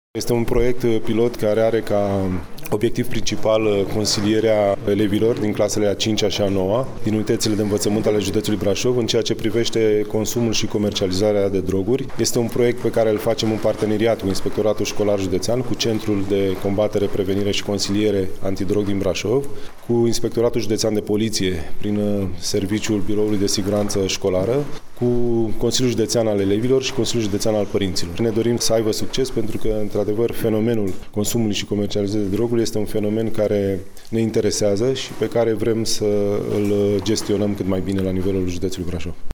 Despre importanța acestuia ne vorbește Prefectul Județului Brașov, Mihai Cătălin Văsii: